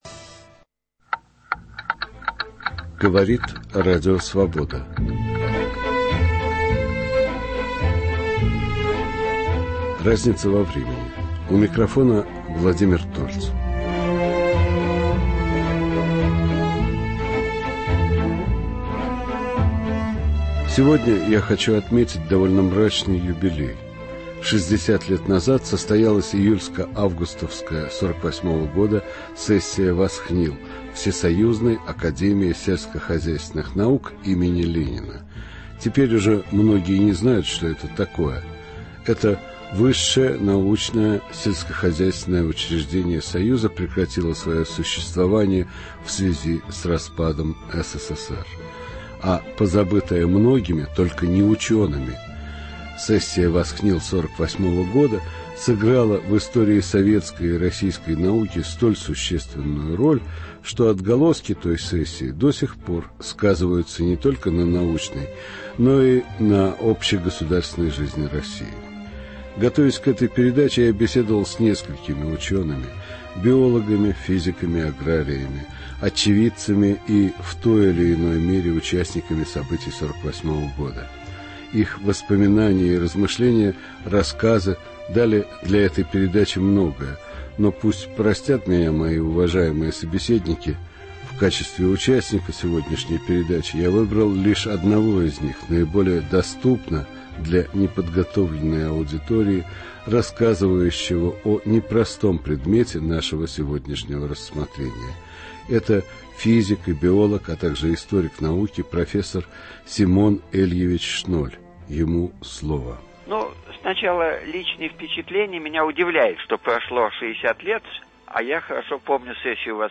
Историк науки Симон Шноль рассказывает о сессии ВАСХНИЛ 1948 г. и ее роковых последствиях для России и ее науки.